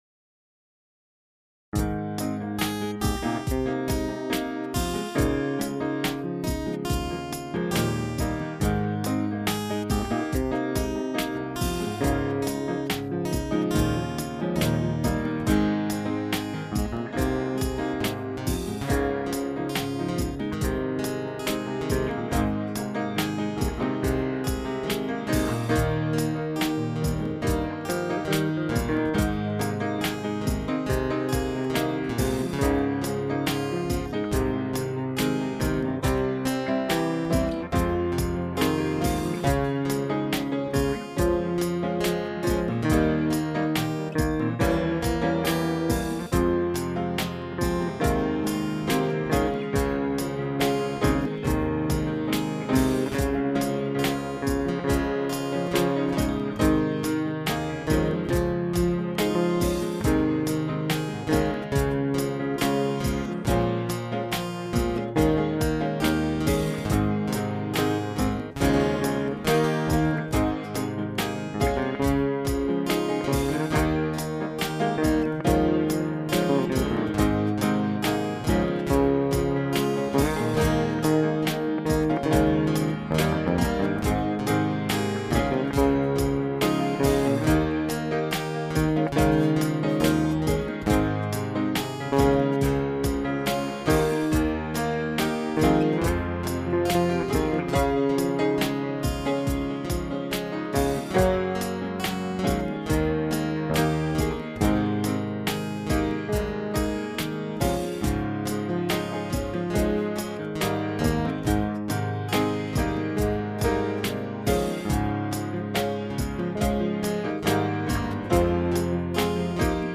No Vocals